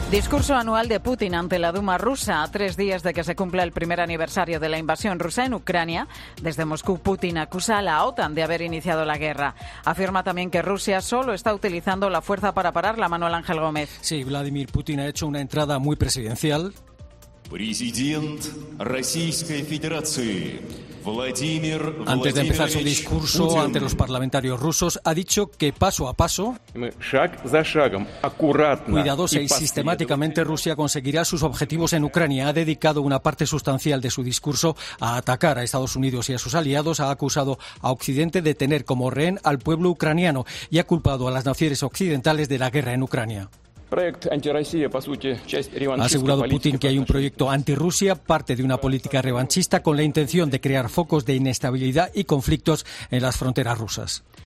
Putin acusa a la OTAN de iniciar la guerra en Ucrania en su discurso ante la Duma